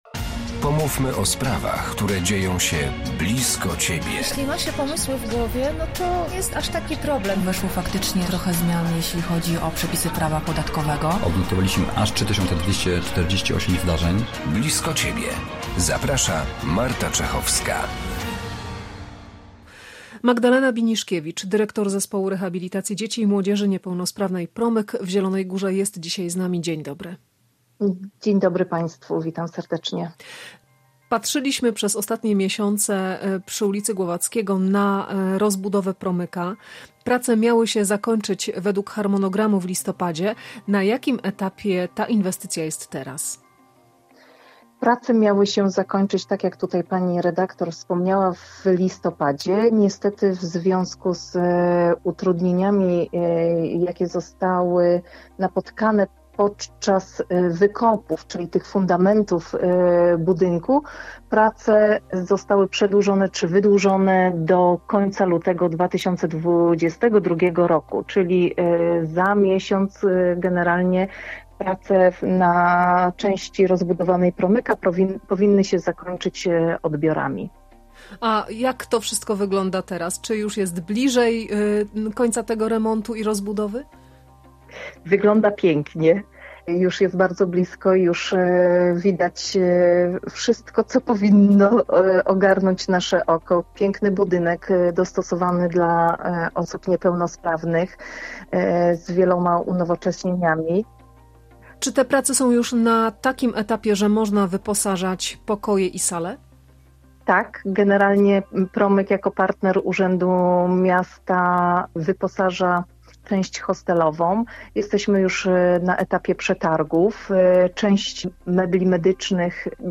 Dzisiaj rozmowa o rozbudowie „Promyka”.